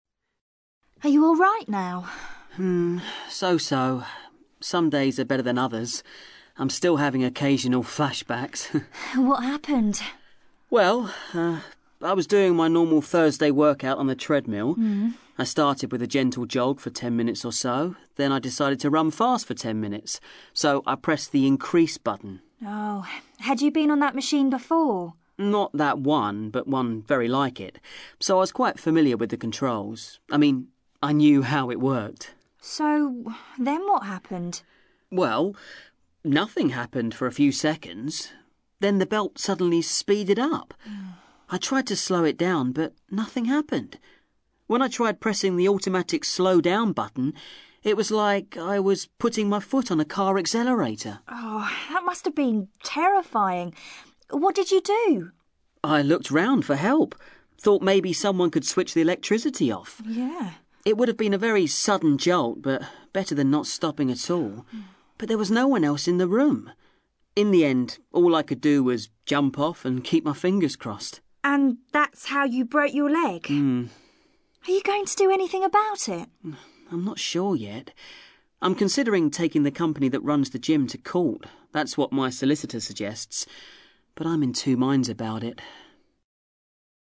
ACTIVITY 129: You are going to hear  two people, Harry and Jasmine, talking about an incident at a gym.